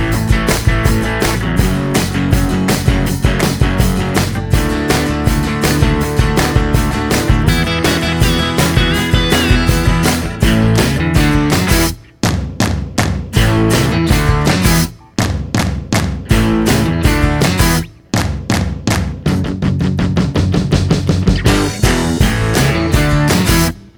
No Backing Vocals Rock 'n' Roll 3:26 Buy £1.50